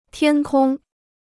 天空 (tiān kōng): Himmel.